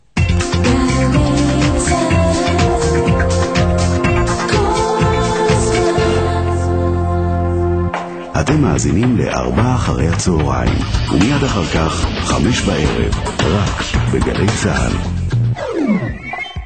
גלי צה”ל מציג: השעון הדובר
speakingClock.mp3